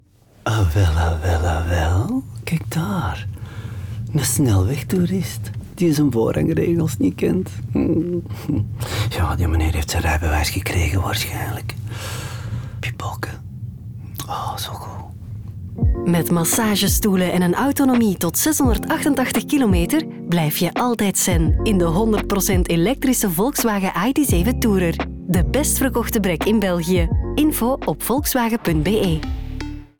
Dat is exact wat er gebeurt in deze reclamespots vol road rage, maar niet zoals we we het gewoon zijn. Bestuurders schelden andere chauffeurs uit met grote woorden… maar doen dat in een opvallend kalme, bijna gelukzalige toon.
Het ludieke contrast tussen de harde uitspraken en de ontspannen manier waarop ze uitgesproken worden, toont perfect hoe de ID.7 Tourer zelfs de meest gespannen verkeerssituaties verandert in pure rust.